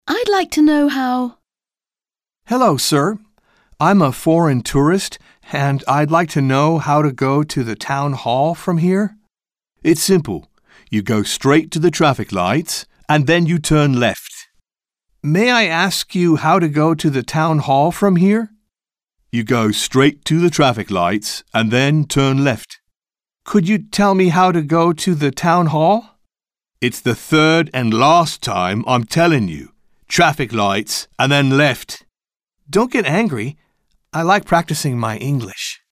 Dialogue - I'd like to know how